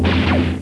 BSG FX - Battlestar Laser
BSG_FX-Battlestar_Laser.WAV